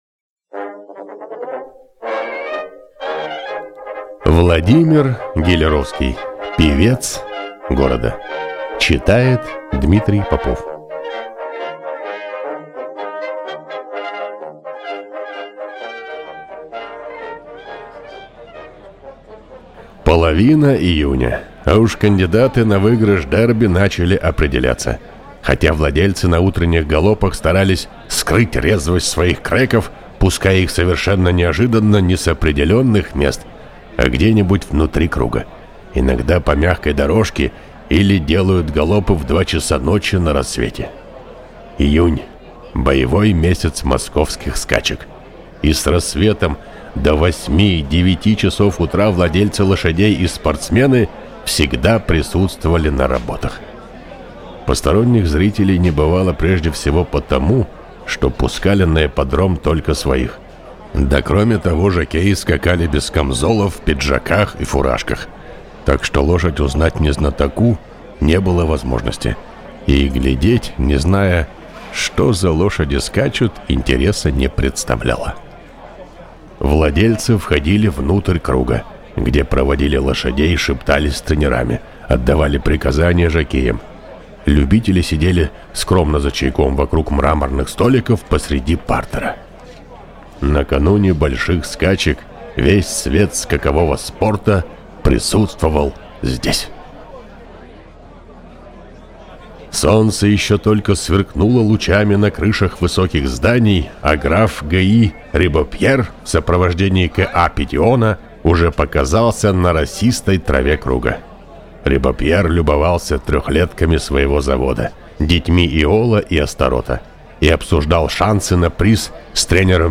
Аудиокнига Певец города | Библиотека аудиокниг